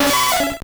Cri de Nidorina dans Pokémon Or et Argent.